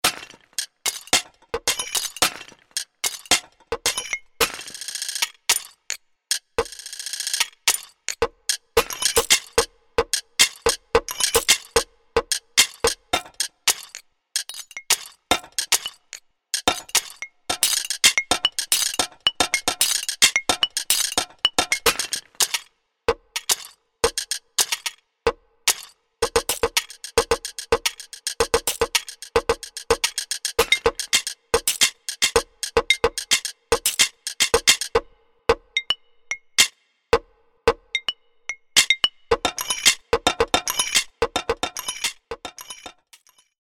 这套独特的鼓组由破碎的陶瓷碎片构成，包含破碎的军鼓和叮当作响的踩镲。
• 10 个循环乐段